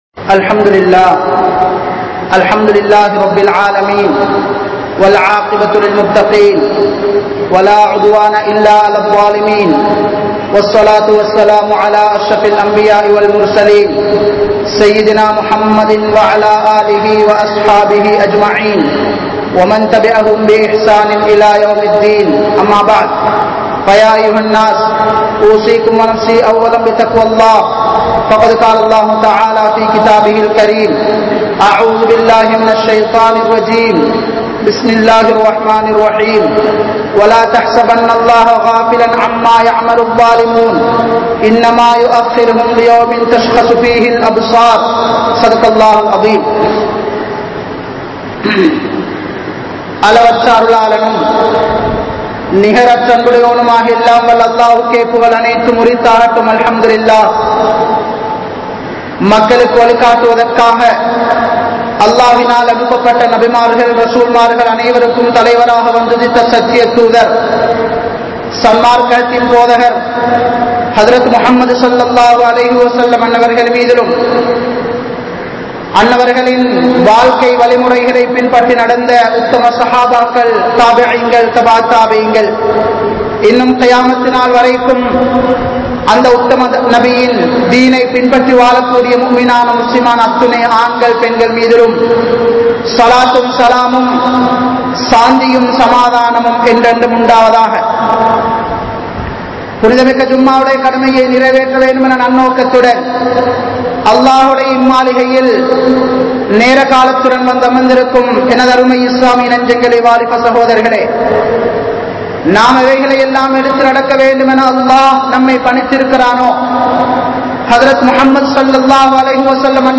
Aniyaayam(Injustice) | Audio Bayans | All Ceylon Muslim Youth Community | Addalaichenai
Grand Jumua Masjith